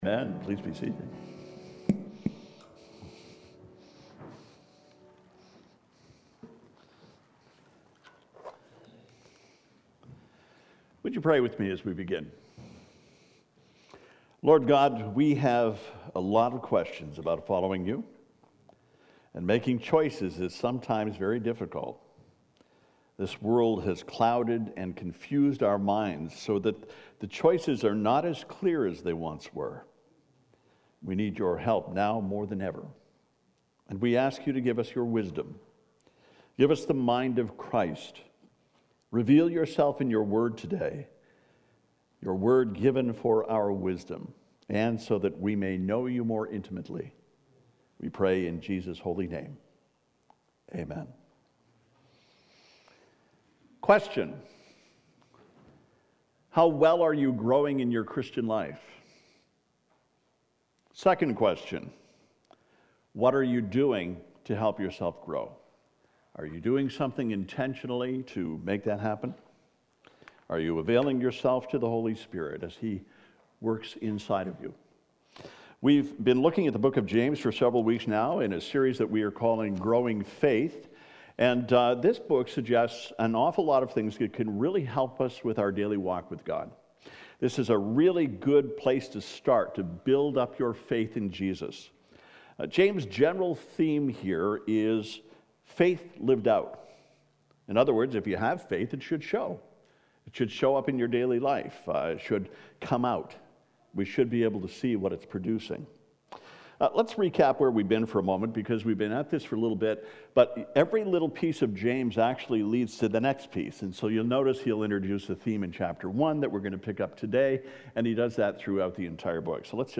April « 2019 « FABIC Sermons